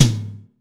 ROOM TOM2A.wav